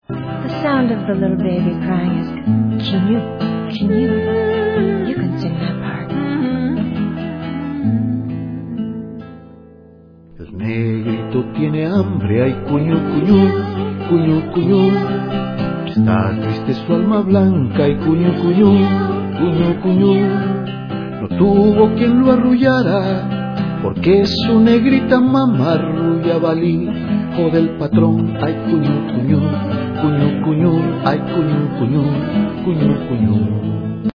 lively story-songs